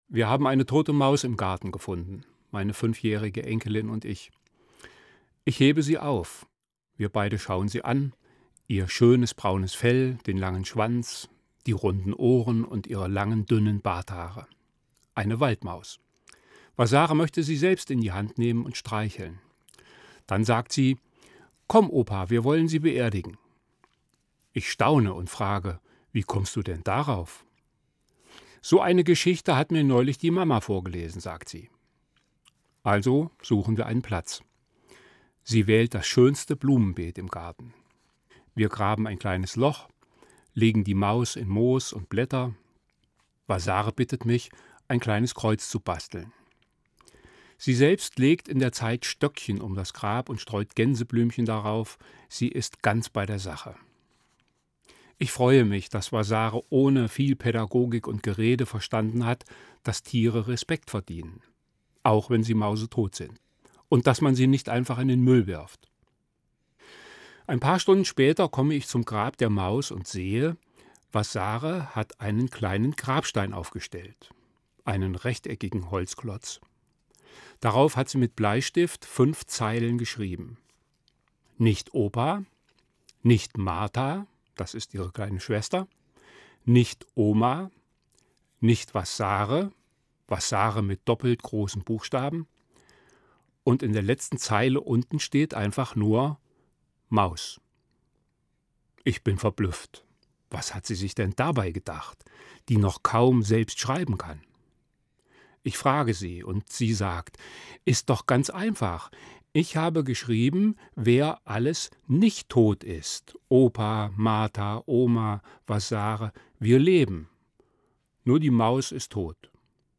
Evangelischer Pfarrer, Marburg